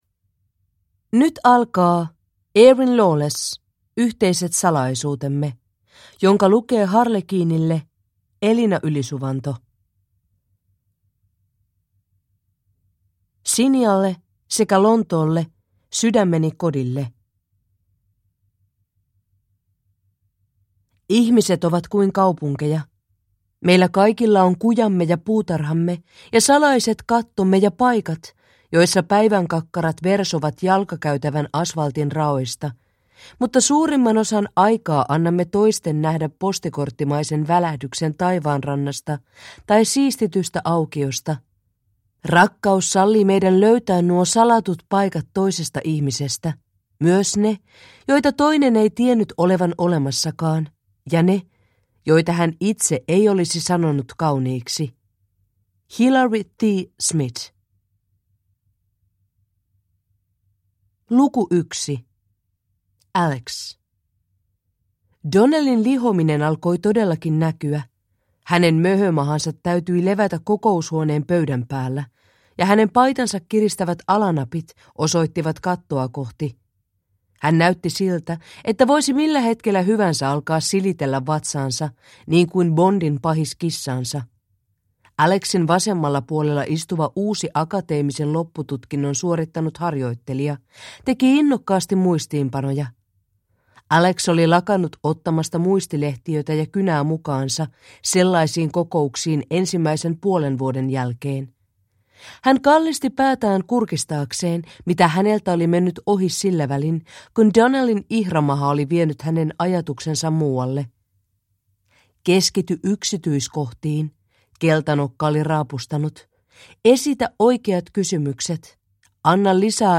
Yhteiset salaisuutemme – Ljudbok